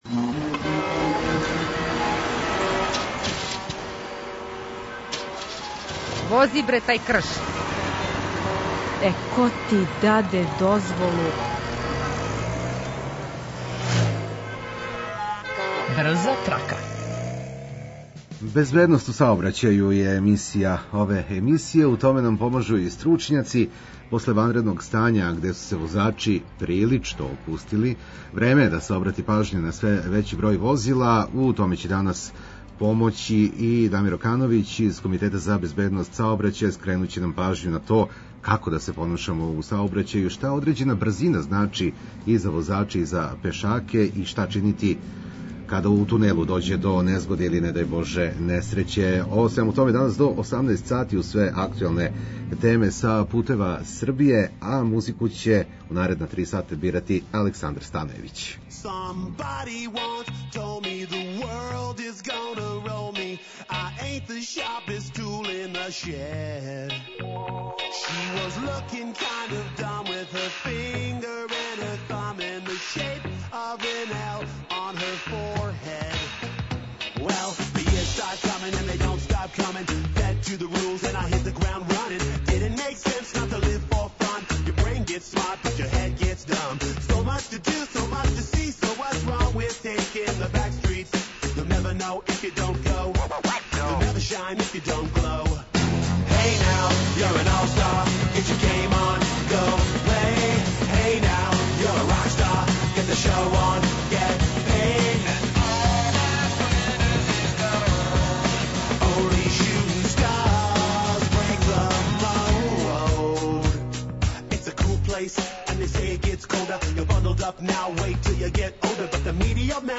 У остатку емисије преносимо детаље о актуелним радовима и алтернативним правцима, пратимо културна дешавања, сервисне информације и возимо уз музику која ће вам улепшати дан.